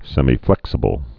(sĕmē-flĕksə-bəl, sĕmī-)